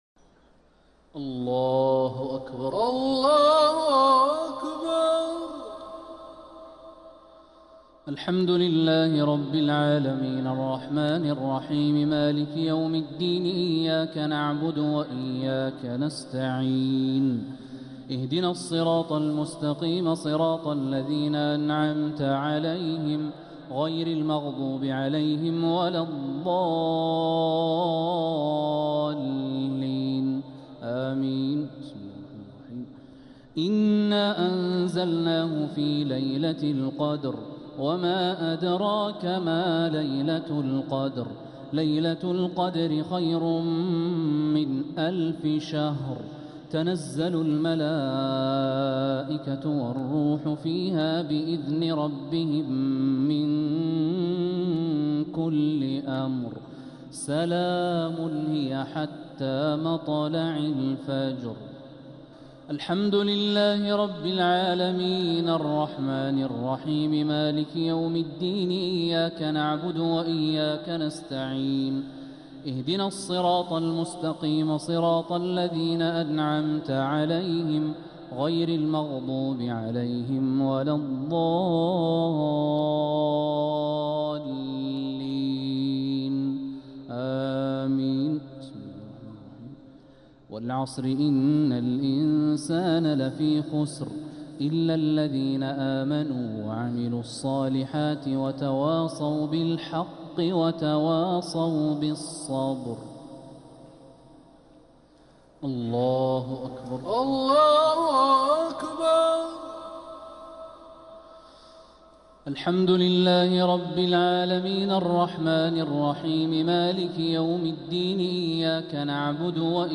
صلاة الشفع و الوتر ليلة 23 رمضان 1446هـ | Witr 23rd night Ramadan 1446H > تراويح الحرم المكي عام 1446 🕋 > التراويح - تلاوات الحرمين